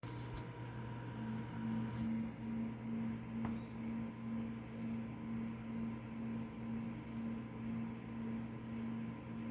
MSI MAG CoreLiquid 240R macht komische Geräusche
Hallo, ich habe das Problem das die Wasserkühlung bei einer Drehgeschwindigkeit von 30-50% diese Geräusche macht, wie in der Audio zuhören sind.